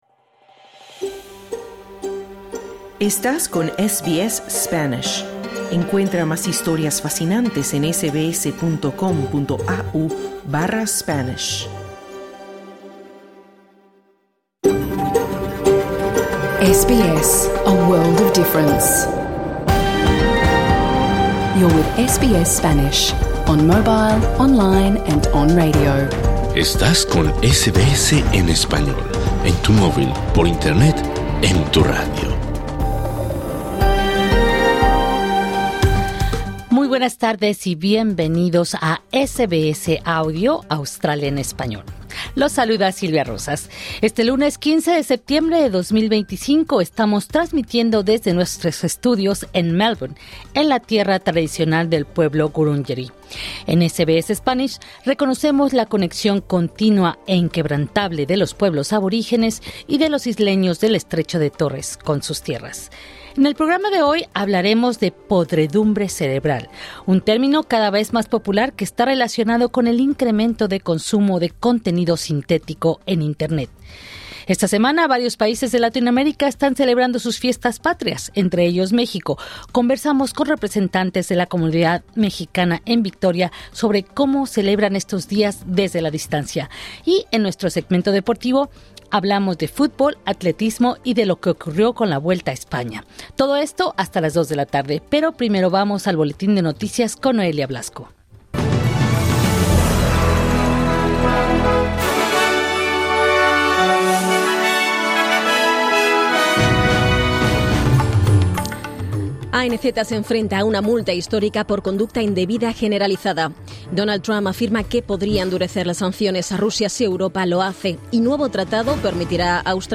Programa en vivo 15 septiembre 2025